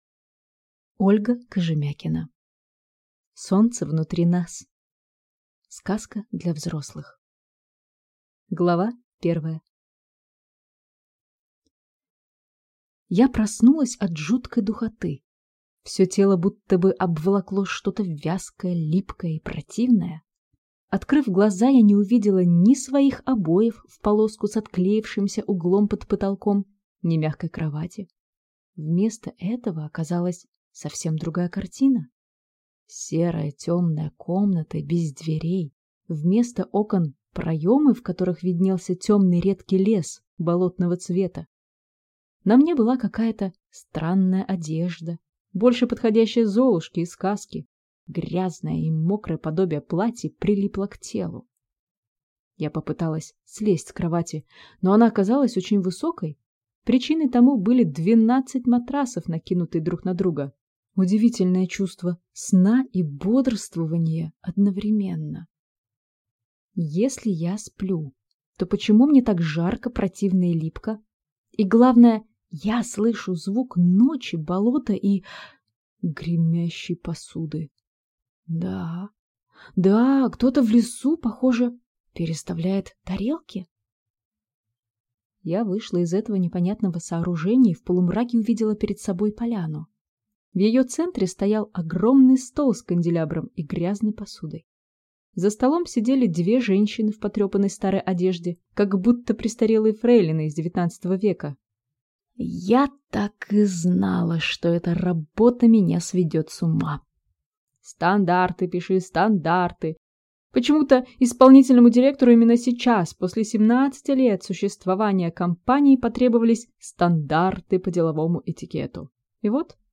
Аудиокнига Солнце внутри нас. Сказка для взрослых | Библиотека аудиокниг